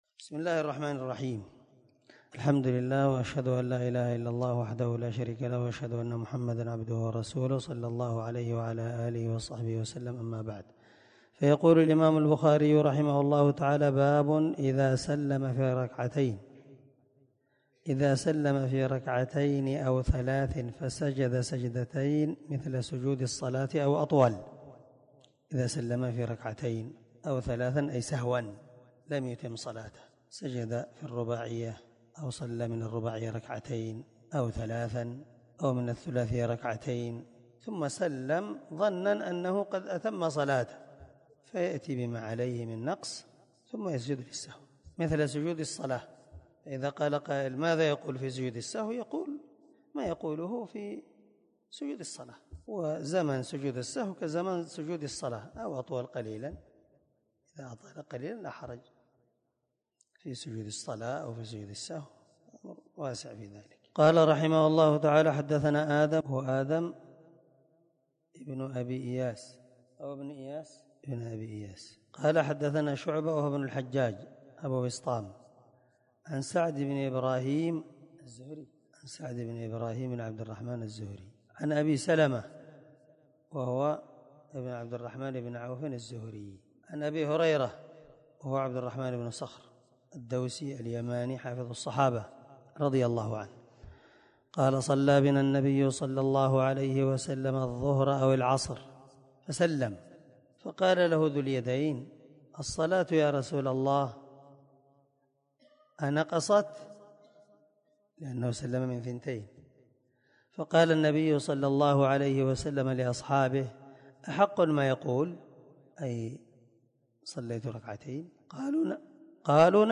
722الدرس_3من_شرح_كتاب_السهو_حديث_رقم1227_من_صحيح_البخاري
دار الحديث- المَحاوِلة- الصبيحة.